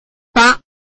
臺灣客語拼音學習網-客語聽讀拼-饒平腔-入聲韻
拼音查詢：【饒平腔】bag ~請點選不同聲調拼音聽聽看!(例字漢字部分屬參考性質)